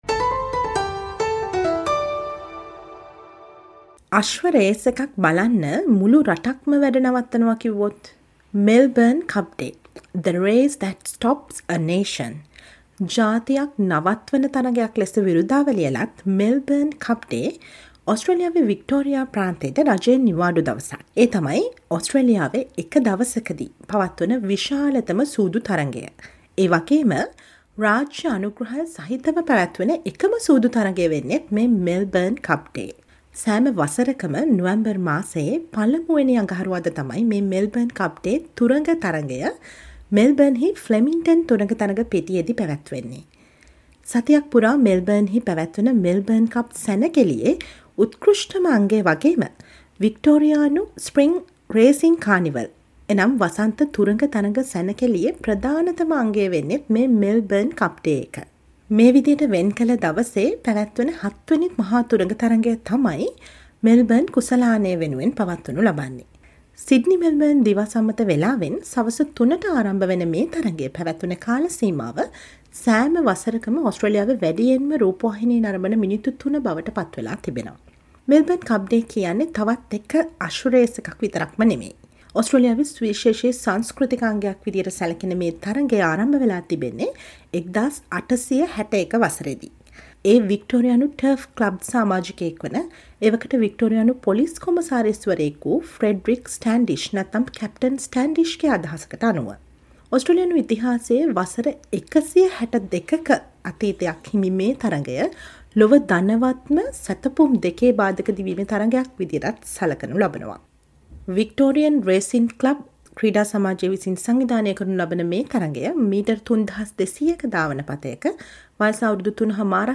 Listen to the SBS sinhala radio special feature revealing different views of Sri Lankan Australians on Melbourne Cup Day.